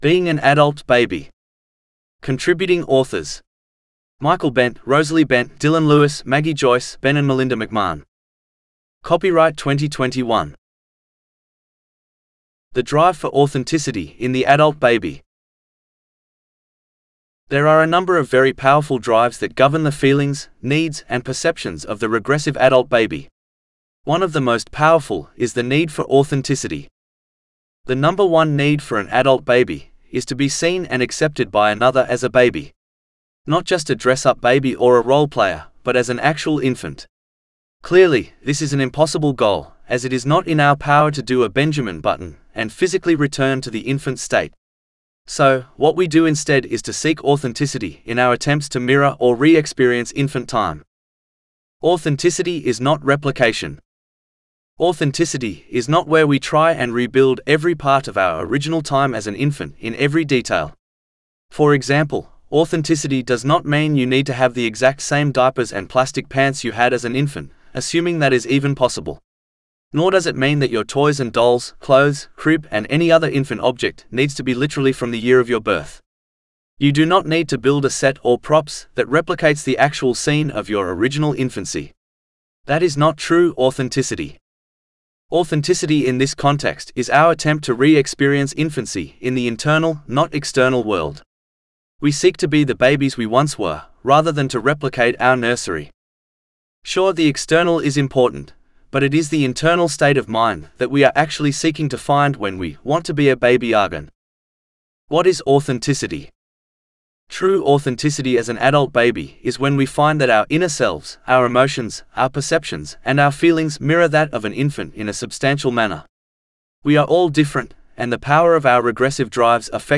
Being An Adult Baby (AUDIOBOOK- male): $US6.75